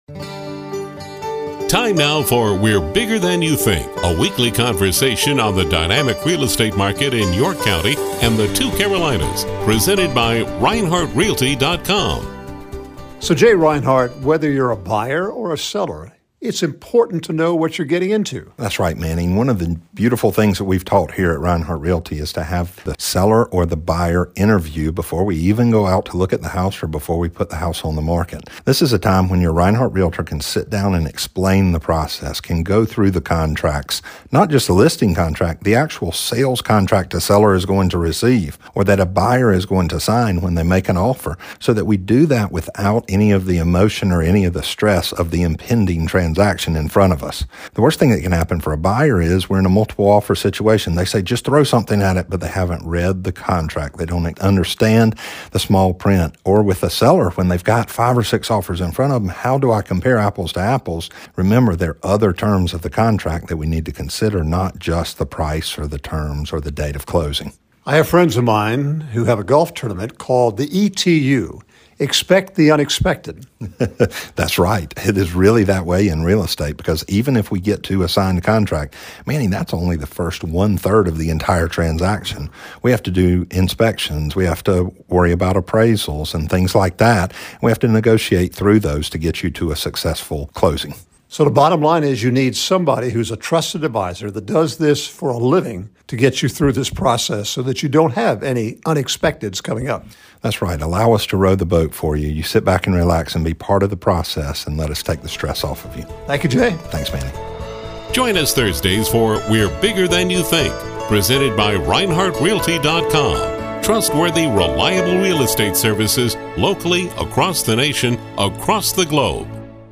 We’re Bigger Than You Think: 06/22/23 Buyer-Seller Interviews